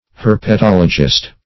\Her`pe*tol"o*gist\